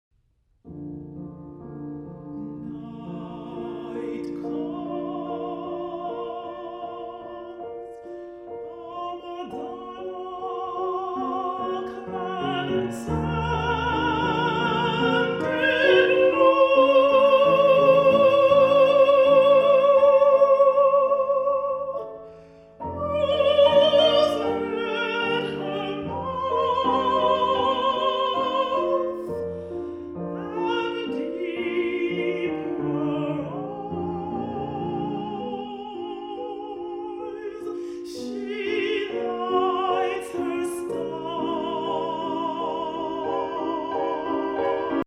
The spiritual